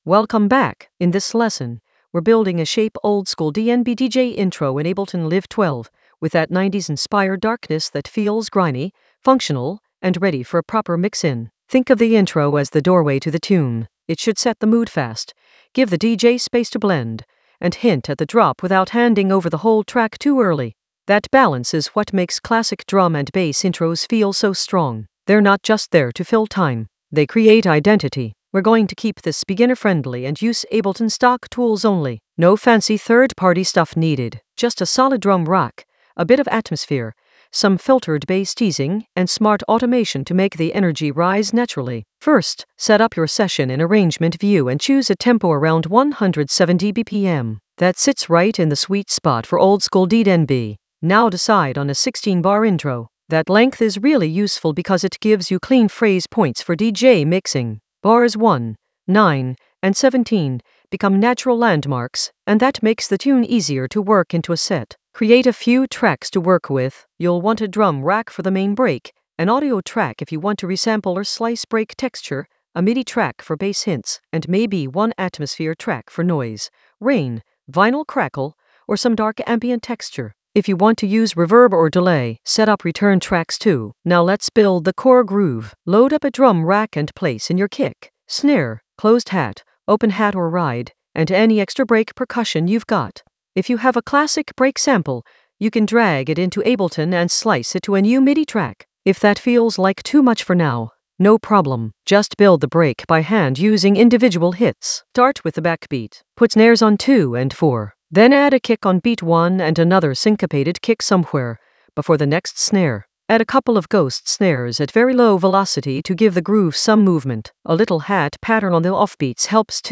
An AI-generated beginner Ableton lesson focused on Shape oldskool DnB DJ intro for 90s-inspired darkness in Ableton Live 12 in the Drums area of drum and bass production.
Narrated lesson audio
The voice track includes the tutorial plus extra teacher commentary.